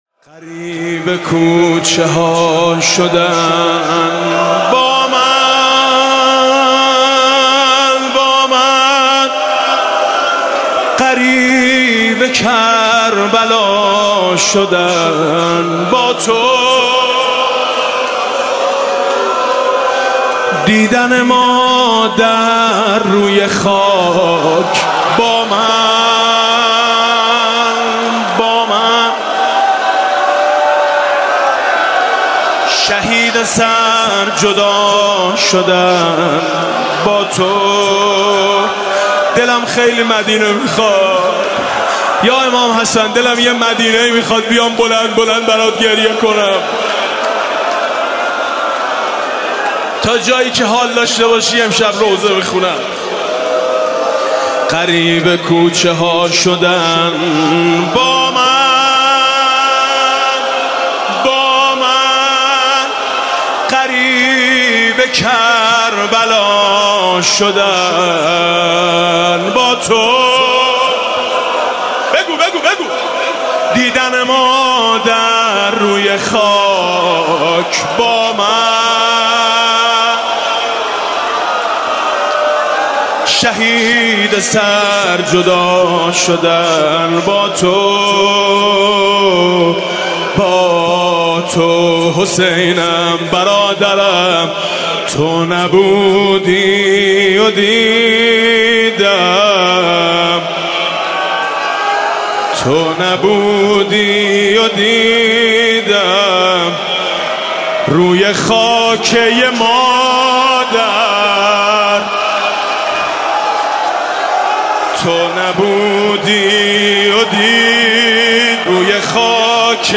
«محرم 1396» (شب پنجم) زمزمه: غریب کوچه ها شدن با من